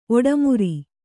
♪ oḍamuri